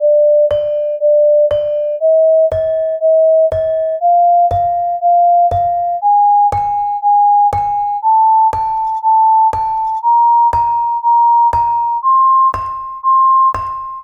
500-millisecond Saron Barung Pélog Tones of Gamelan Kyai Parijata Compared with Sine Tones of the Same Frequency
gamelan, saron barung, frequencies, spectra